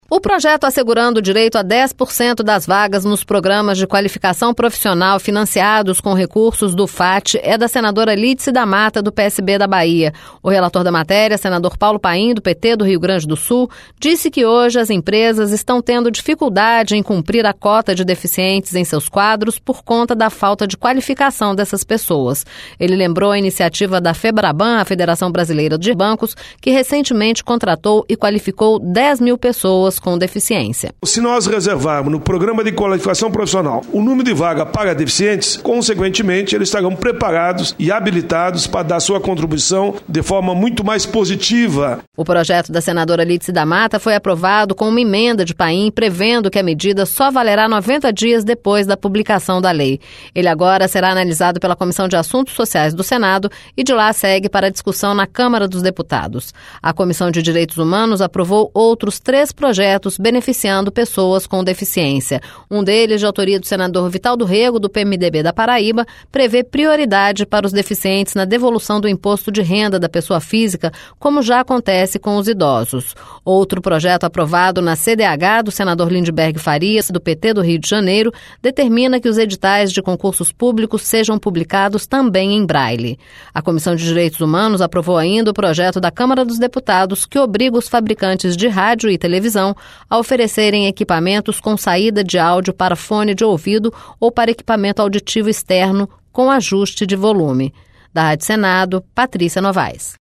Senador Paulo Paim